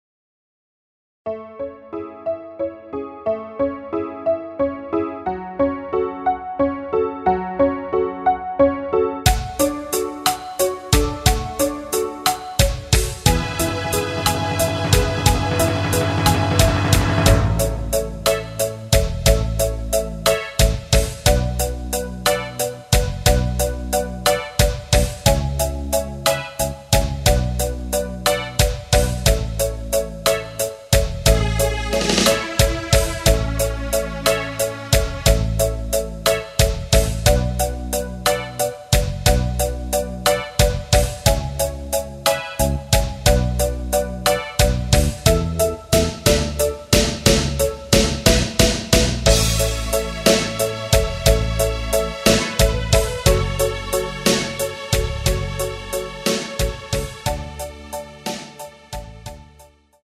*부담없이즐기는 심플한 MR~
앞부분30초, 뒷부분30초씩 편집해서 올려 드리고 있습니다.
중간에 음이 끈어지고 다시 나오는 이유는